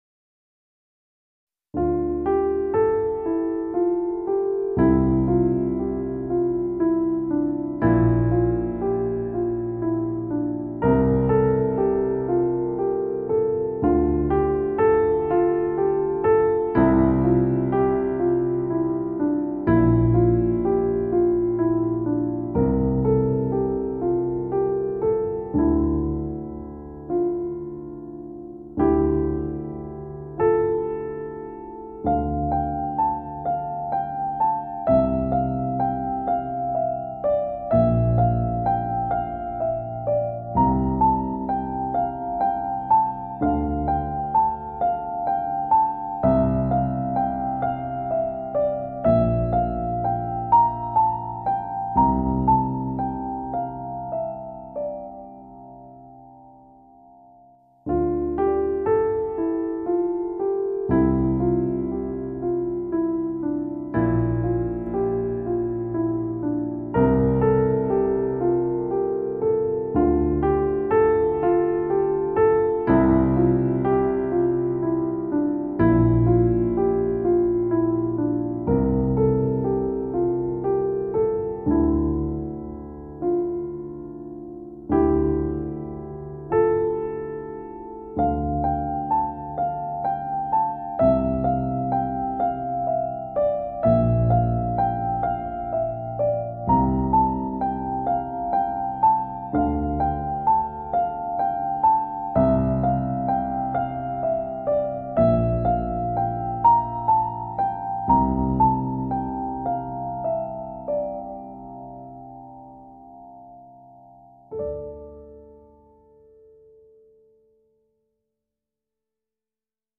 tema dizi müziği, duygusal huzurlu rahatlatıcı fon müziği.